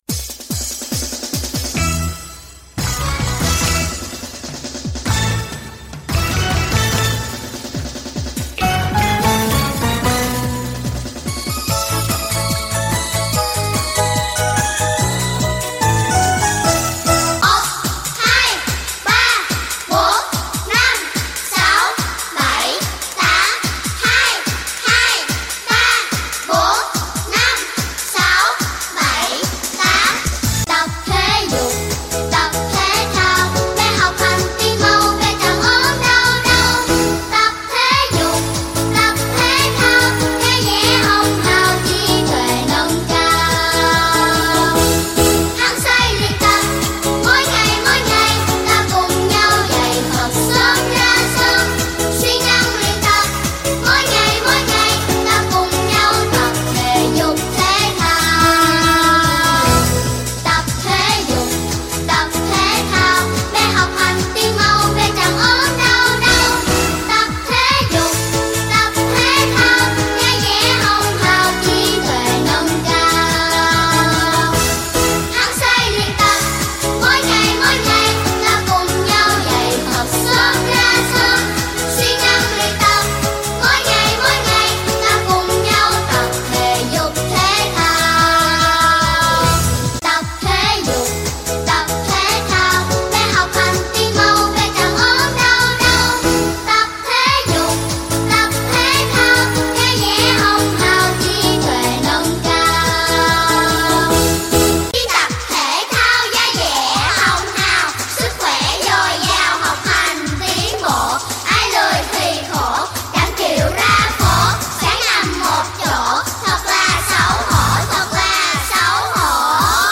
Nhạc thể dục giữa giờ năm học 2023-2024 - Trường TH Mỹ Đức 1